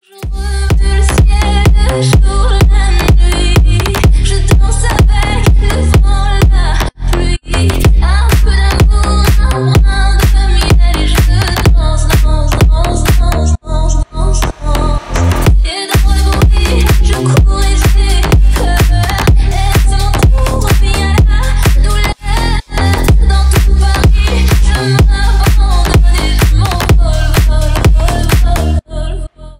Ремикс # Поп Музыка
клубные